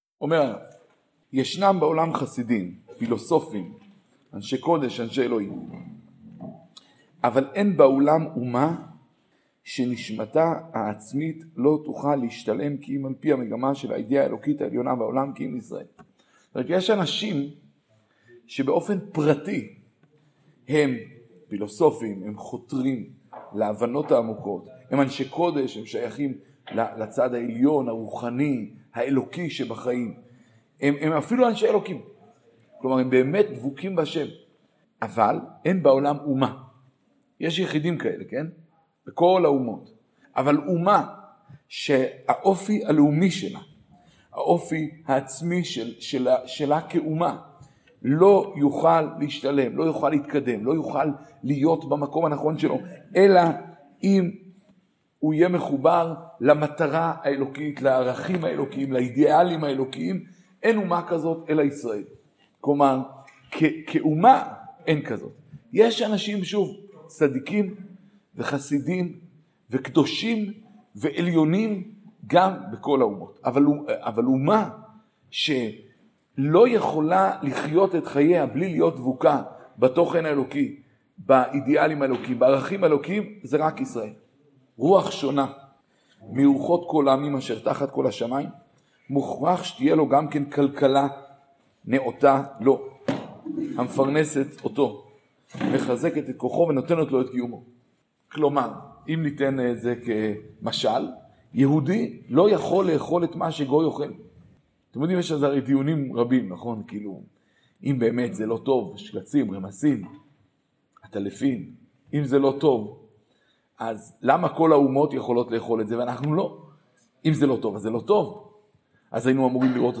שיעורים באורות התחיה